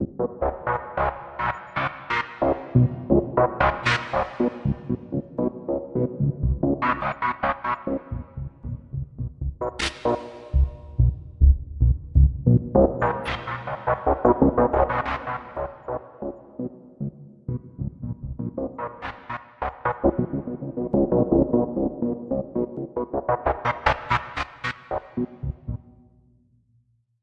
描述：电子贝司声摆，具有光谱和节奏的变化
Tag: 低音 电子 重复 序列